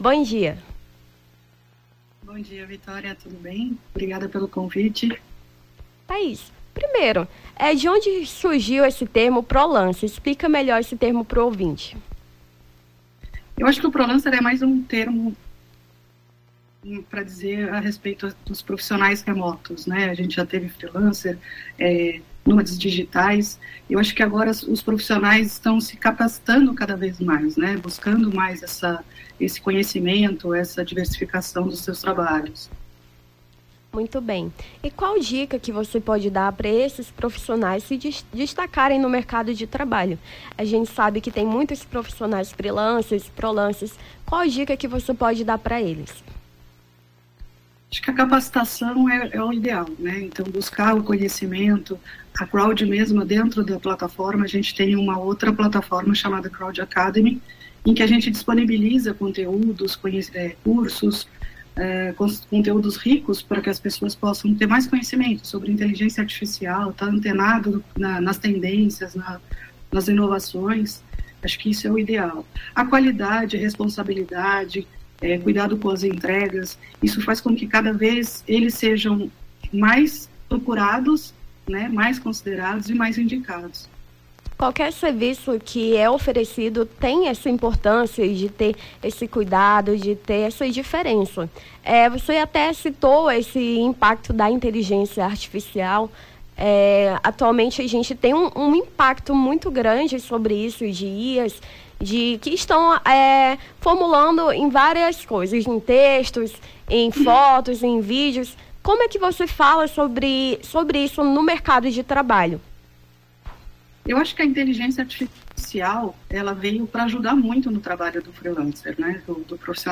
Nome do Artista - CENSURA - ENTREVISTA (PROLANCERS) 14-07-23.mp3